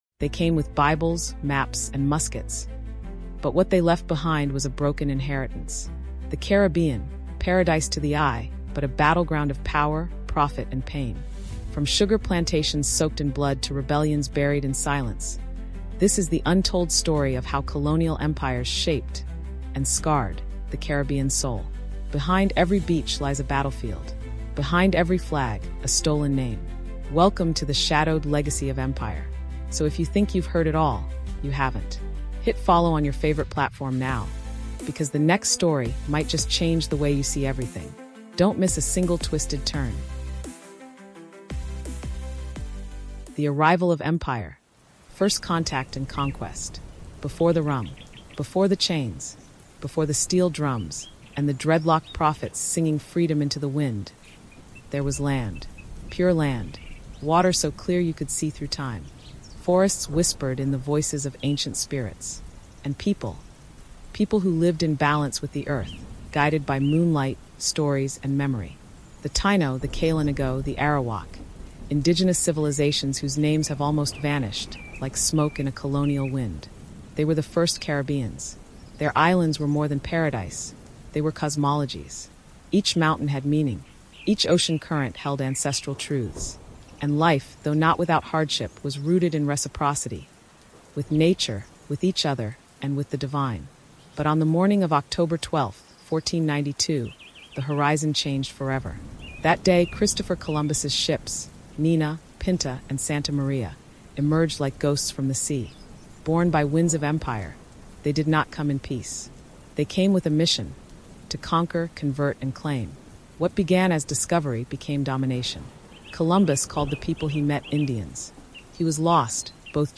This 10-part Caribbean history audiobook documentary explores the brutal legacy and cultural resilience of the Caribbean, as told through the voices of history experts, storytellers, and Caribbean podcast scholars.
Narrated with cinematic depth, each chapter connects Jamaican music, Caribbean literature, black history, and art with the wider struggles of the Caribbean diaspora. Listeners will uncover hidden truths about sugar plantations, betrayal during emancipation, and the illusion of independence — all told with a deep reverence for Caribbean identity and heritage.